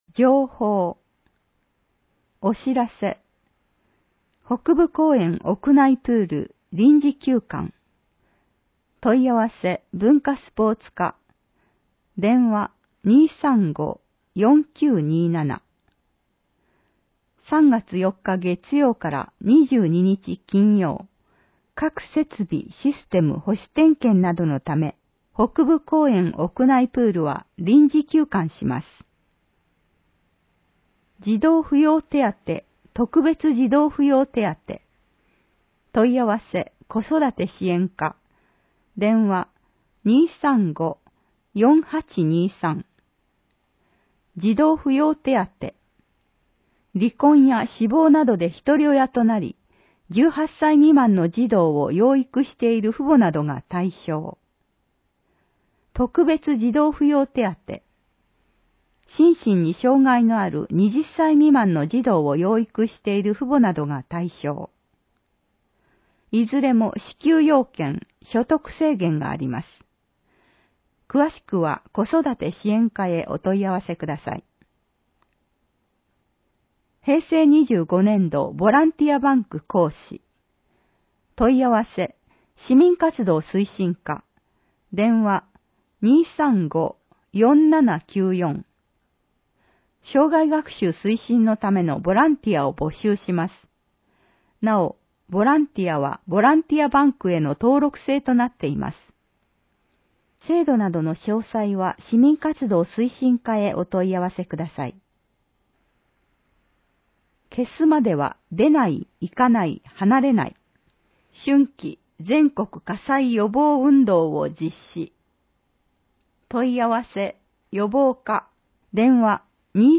※音声版は、音声訳ボランティア「矢ぐるまの会」の協力により、同会が視覚障がい者の方のために作成したものを登載しています。